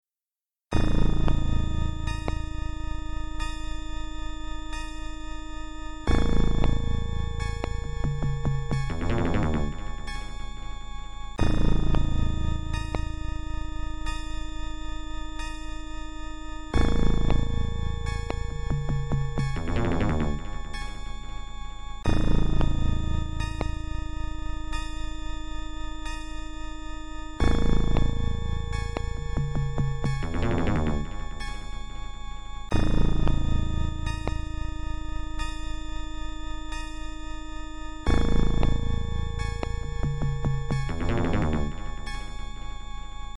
Music & sound effects